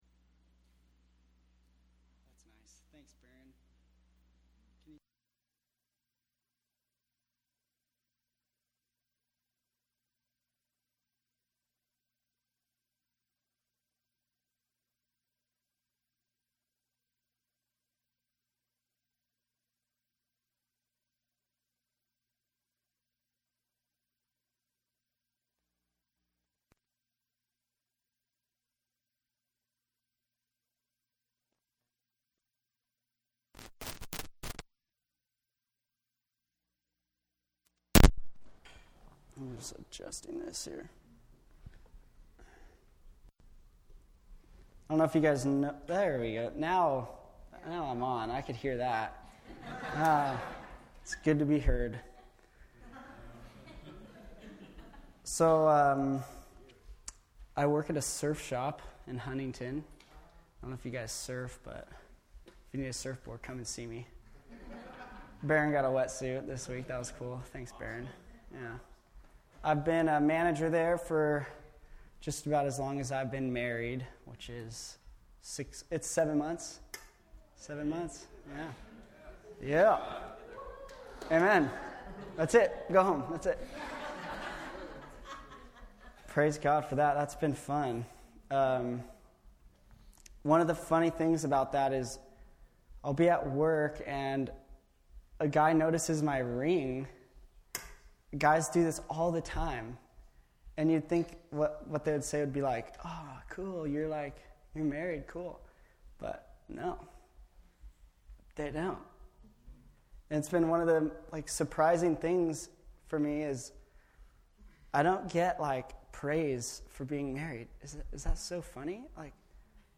FWC Sermons Service Type: Sunday Morning